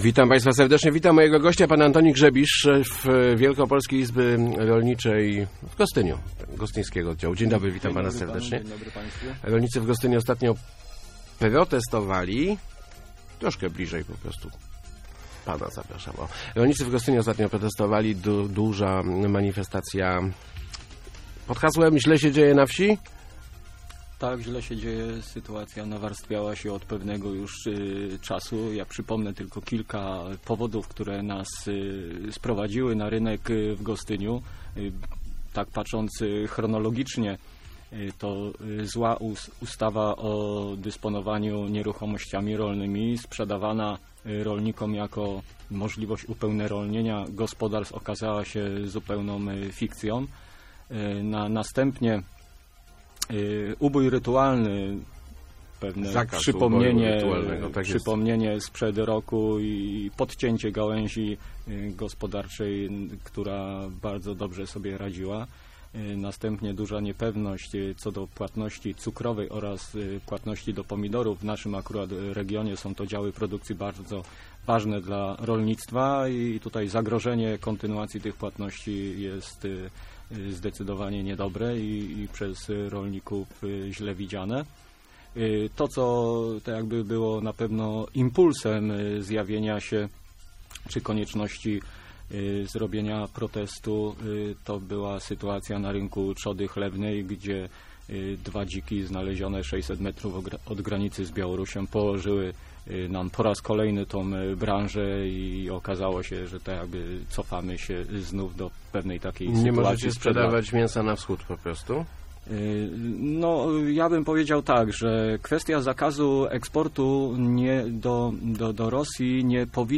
Produkcja wieprzowiny jest kompletnie nieopłacalna - mówił w Rozmowach Elki